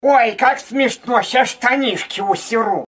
• Качество: 320, Stereo
смешные
голосовые